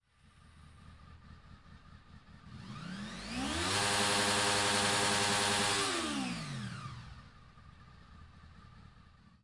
09 咆哮